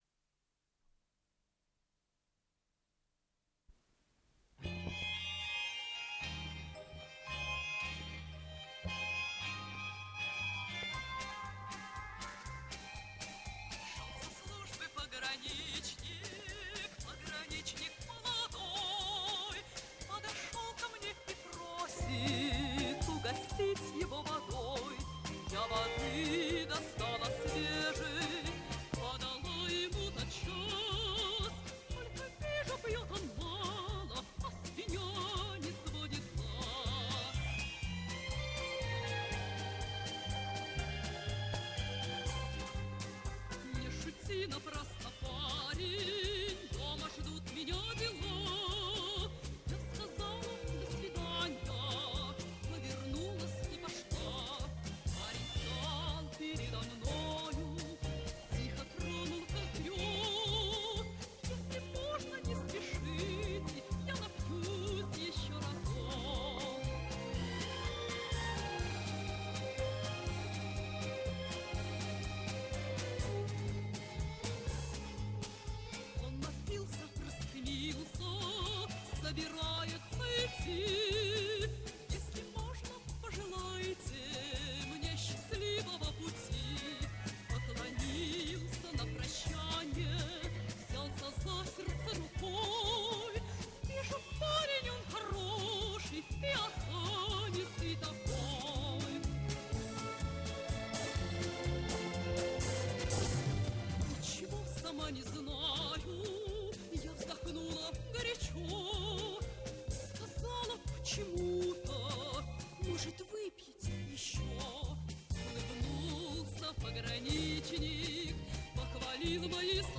Дубль моно.